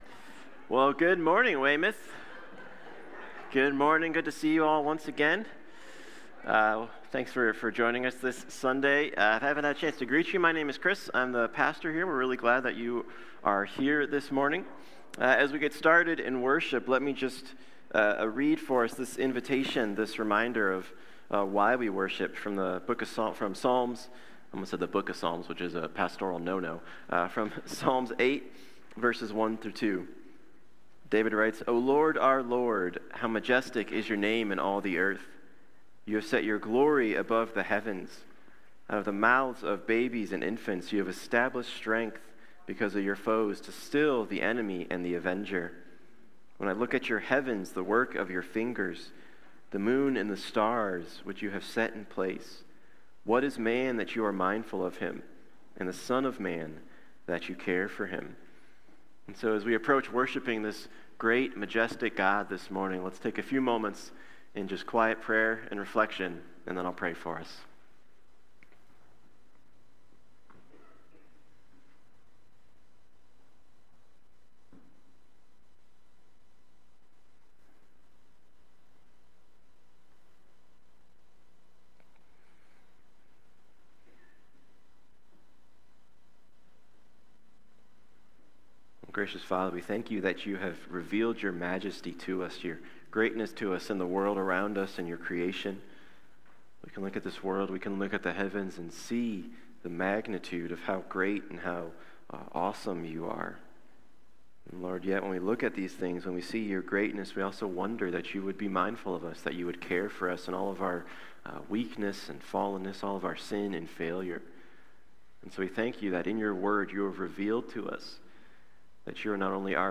Series: 1 Peter , Living in Exile Passage: 1 Peter 4:1-6 Service Type: Sunday Morning Click on title above to watch video.